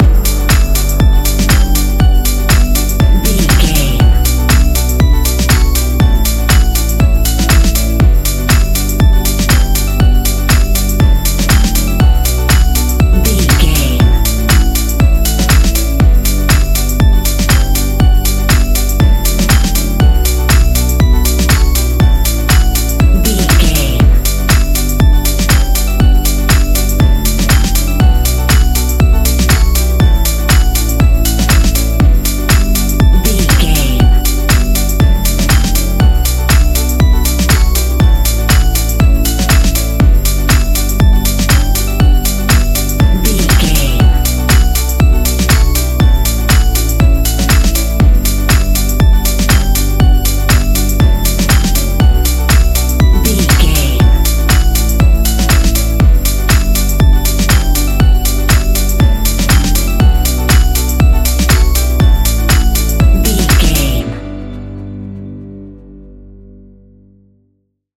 Ionian/Major
E♭
house
electro dance
synths
techno
trance